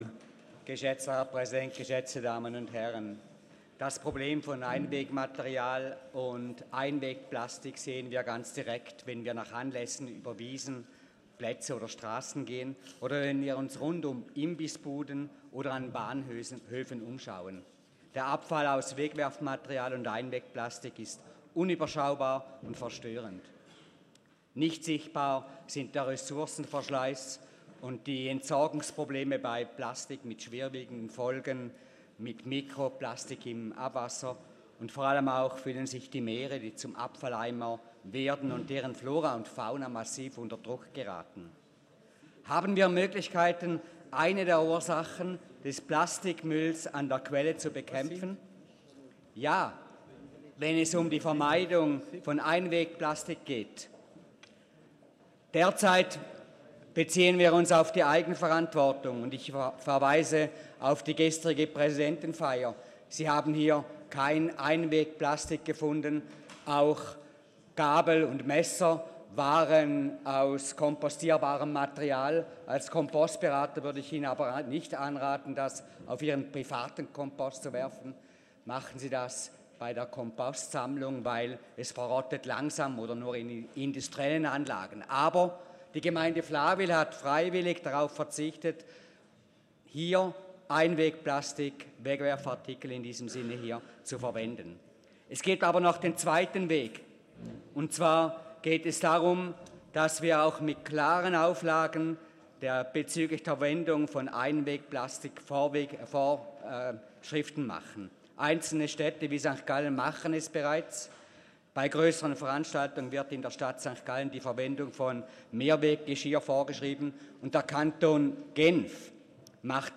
Session des Kantonsrates vom 11. bis 13. Juni 2019